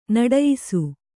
♪ naḍayisu